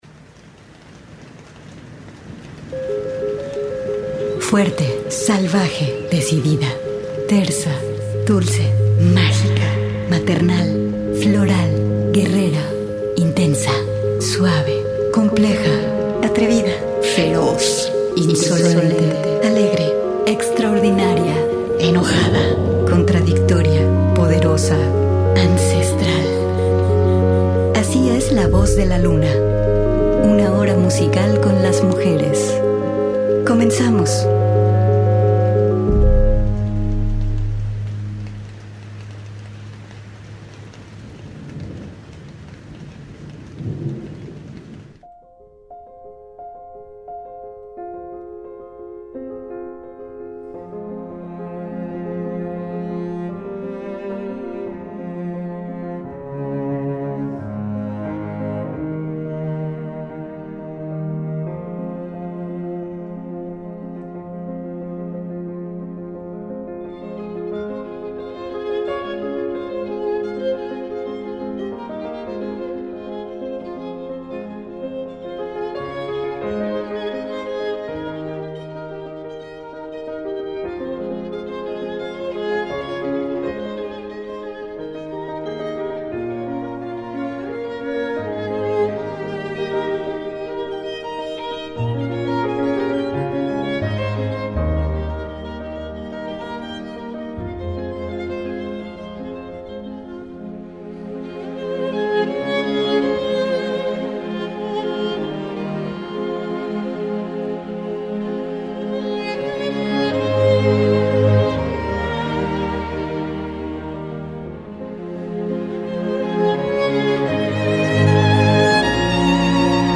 un espacio de música hecha por mujeres ?